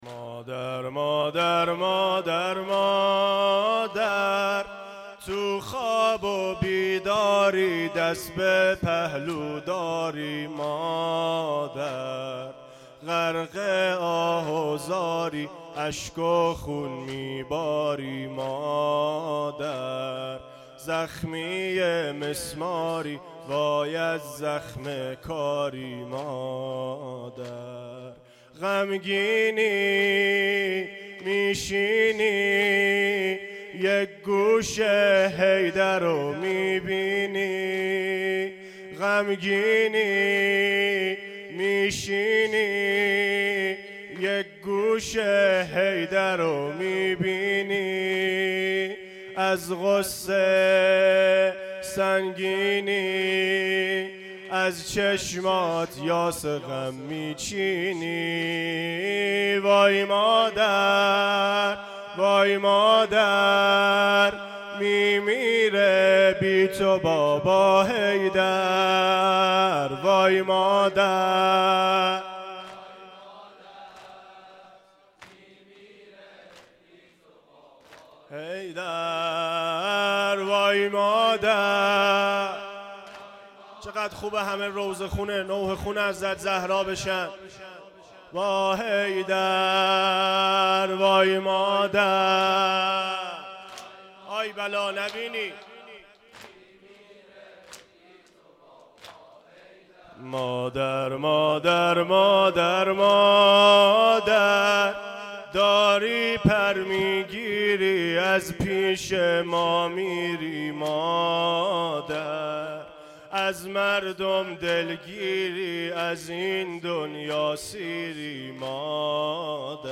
مداحی
زمینه.mp3